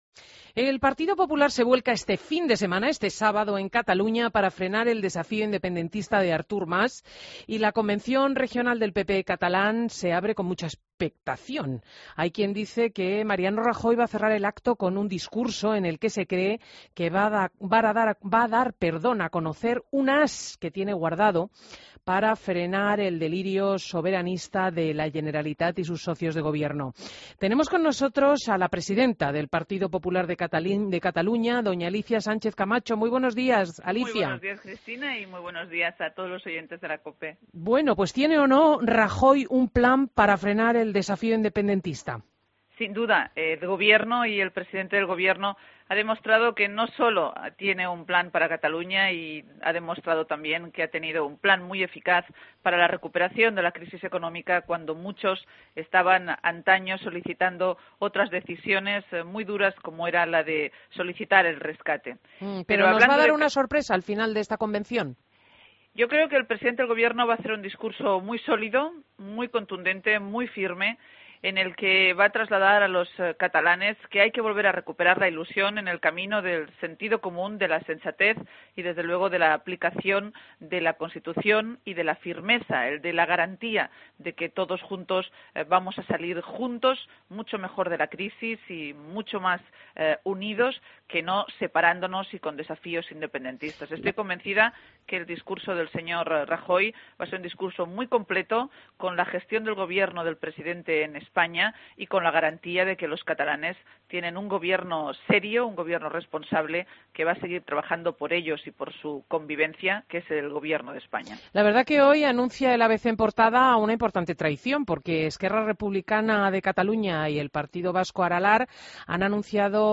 Entrevista a Alicia Sánchez Camacho en Fin de Semana COPE